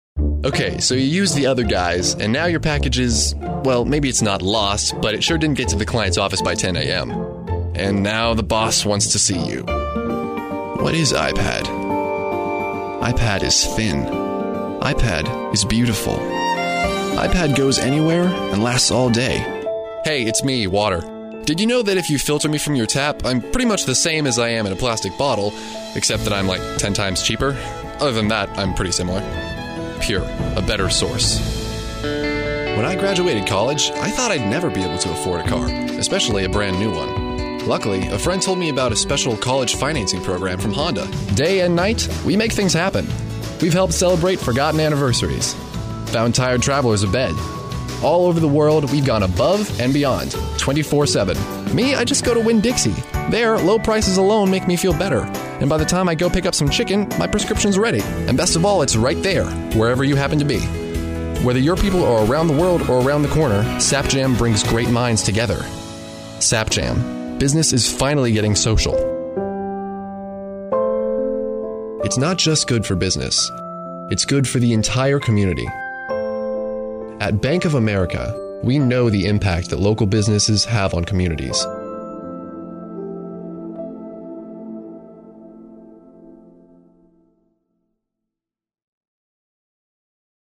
Never any Artificial Voices used, unlike other sites.
Yng Adult (18-29)